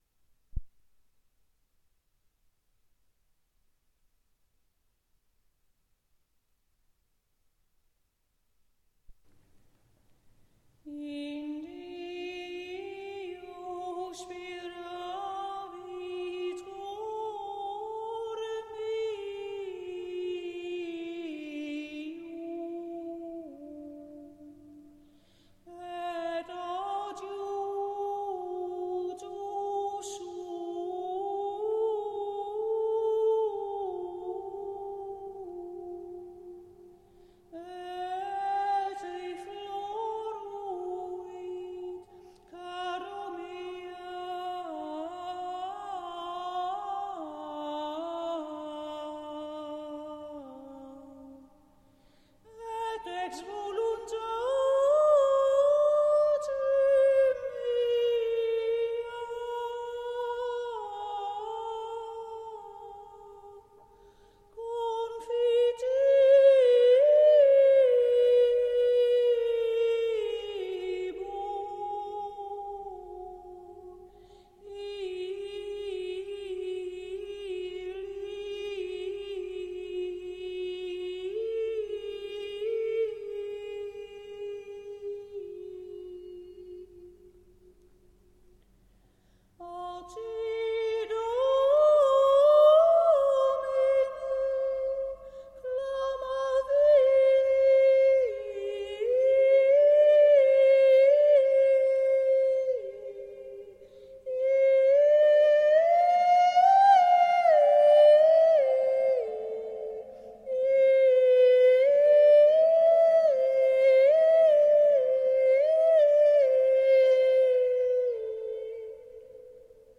In Deo speravit (Ps. 27, 7.1), graduale A en C  WMP   RealPlayer